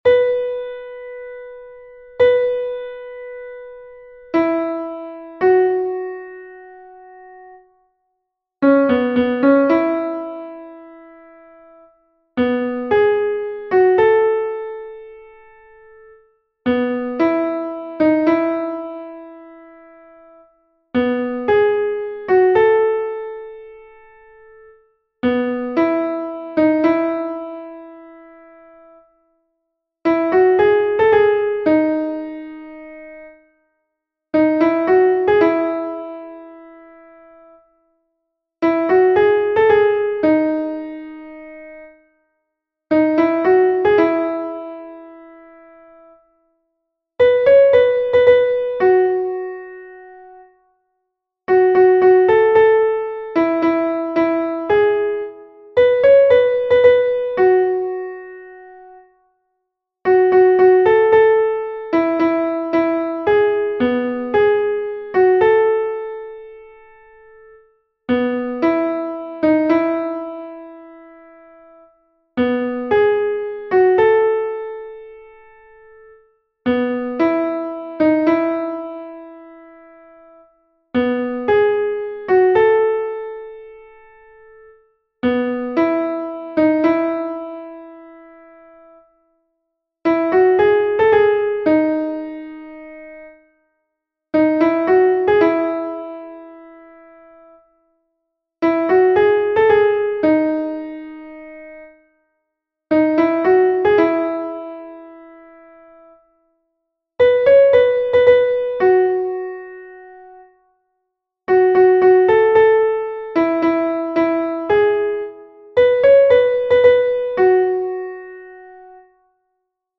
I have a dream-Mezzo-soprano - Chorale Concordia 1850 Saverne